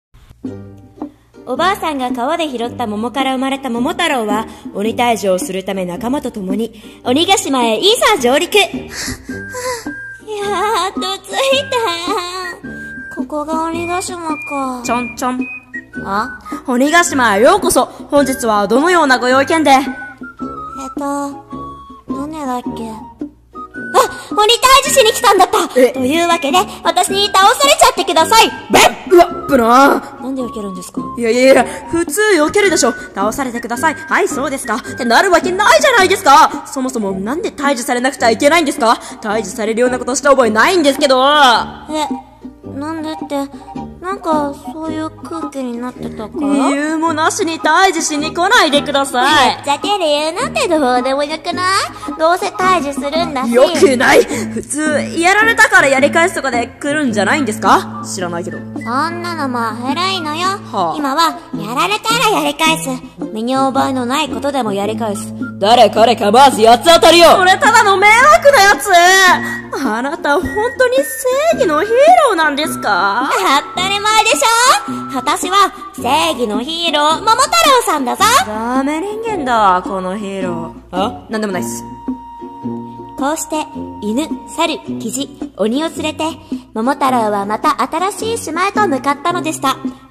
【声劇】こんな桃太郎ってあり？！